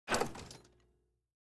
八音盒打开.ogg